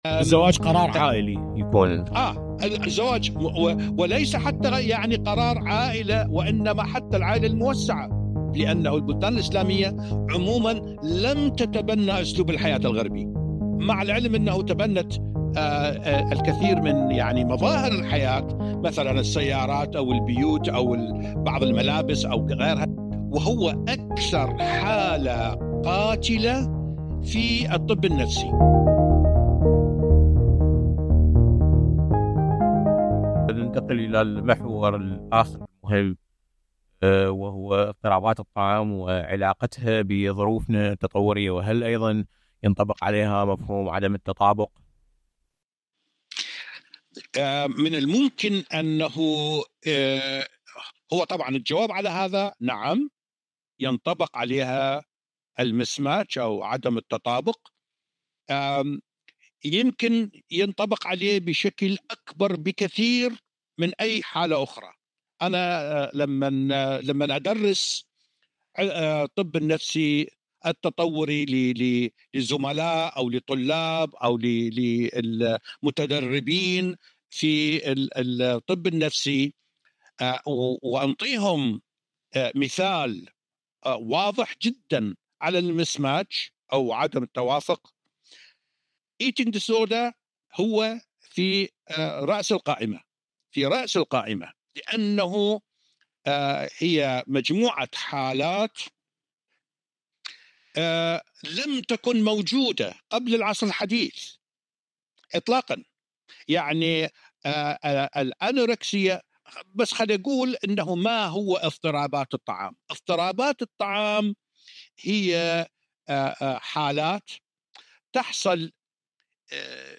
الانوركسيا واضطرابات الطعام عامة في الطب النفسي التطوري: لقاء